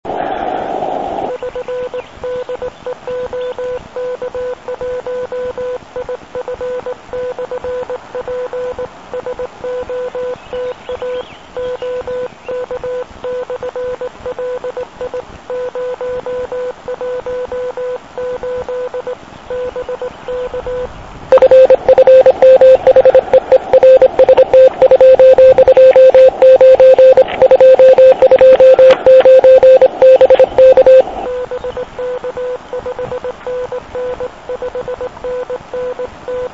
Ovšem poslech v místě, kde není široko daleko žádné elektrické vedení - to je zážitek. A jak jsem některé z Vás slyšel?